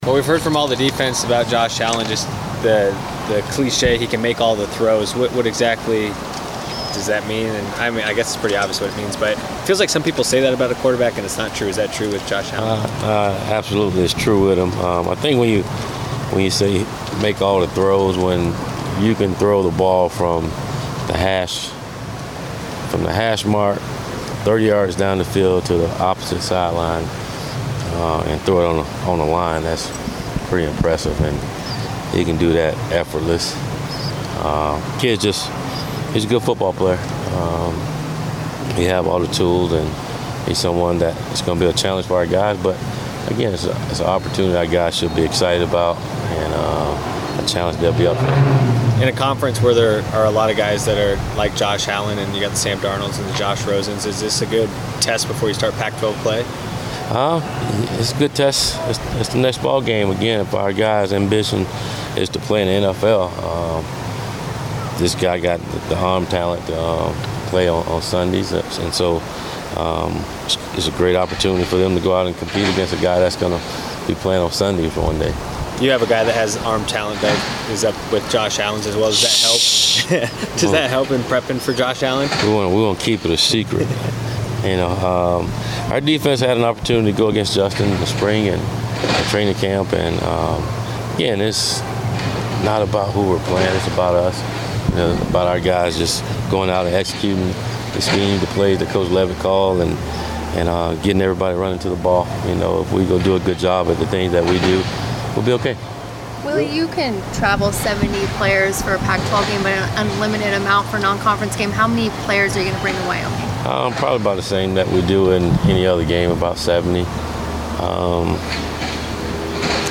Willie Taggart Media Session 9-13-17